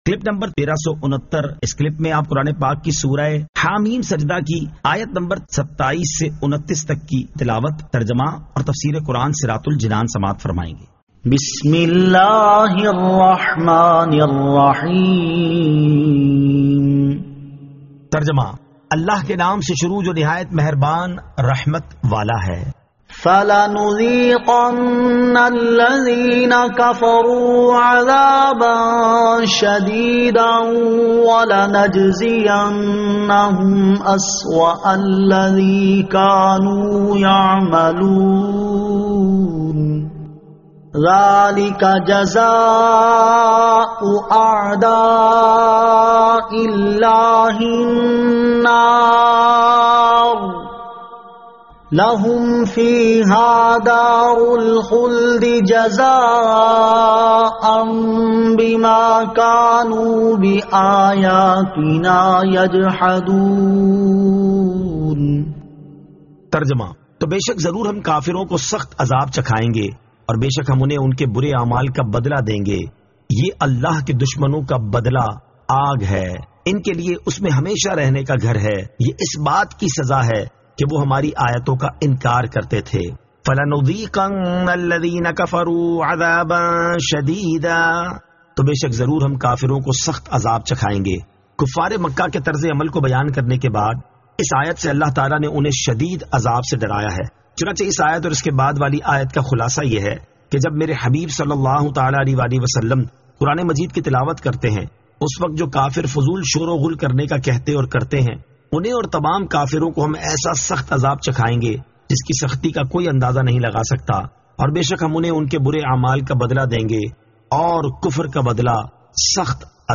Surah Ha-Meem As-Sajdah 27 To 29 Tilawat , Tarjama , Tafseer